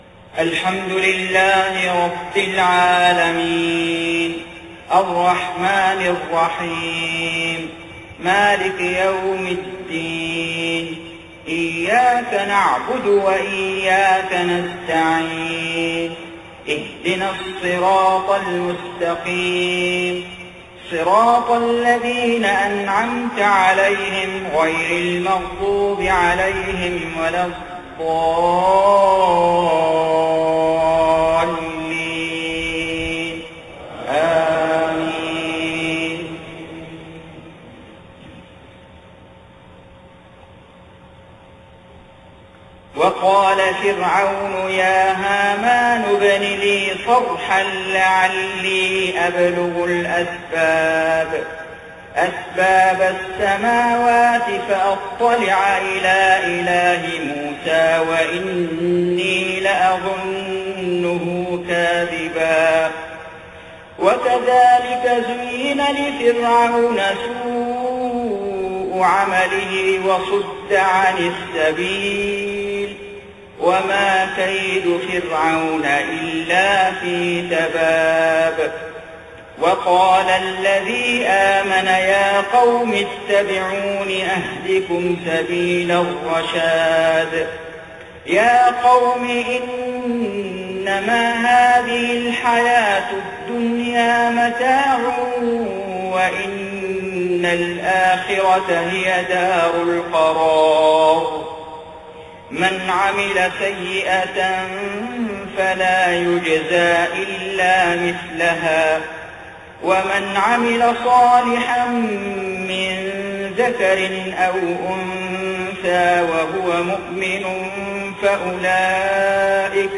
صلاة العشاء 29 محرم 1430هـ من سورة غافر 36-52 > 1430 🕋 > الفروض - تلاوات الحرمين